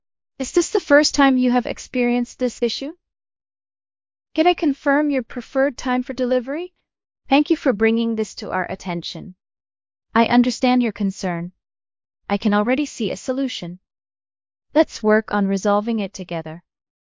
Krisp’s Accent Conversion v3.7 model delivers significant improvements in naturalness, pronunciation accuracy, speaker similarity, voice stability, and audio clarity.
Indian